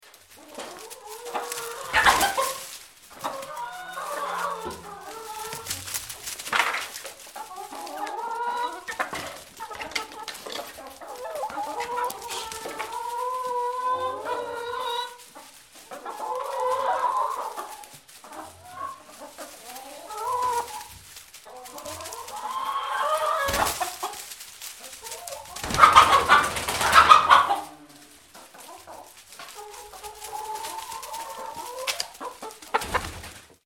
Huehnerstall.mp3